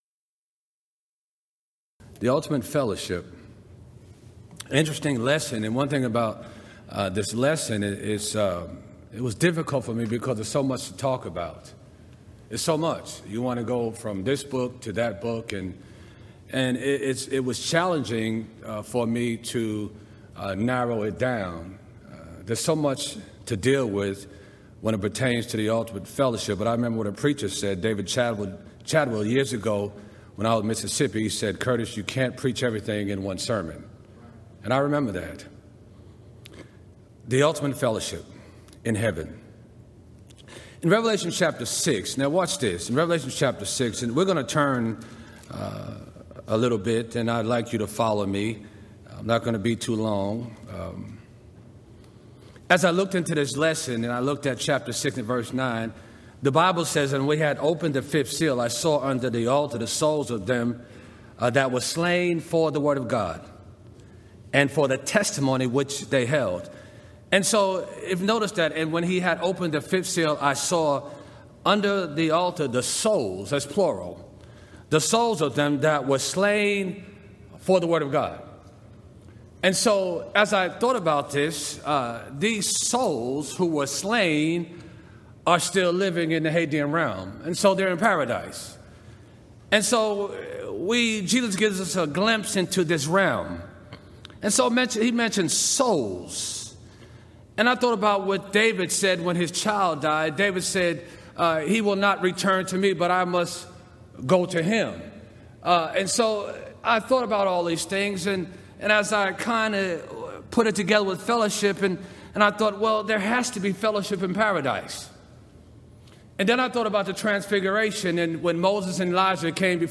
Event: 24th Annual Gulf Coast Lectures
lecture